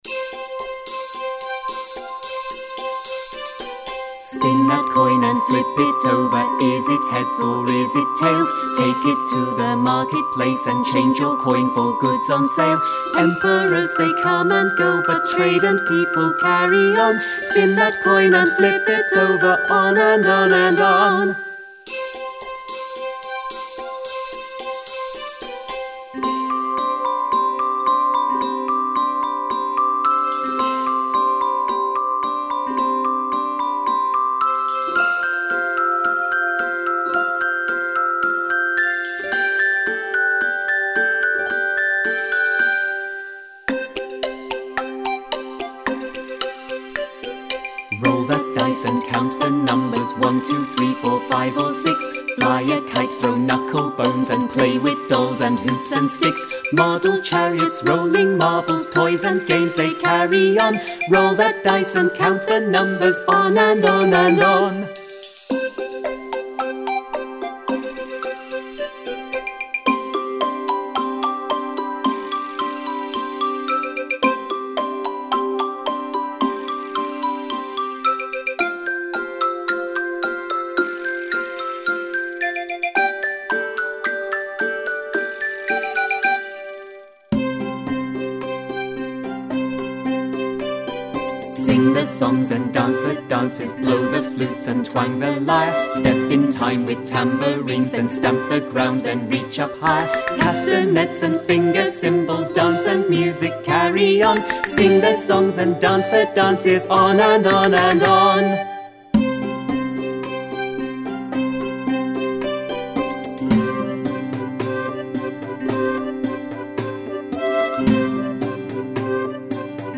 Practise singing the song with the full-vocal track.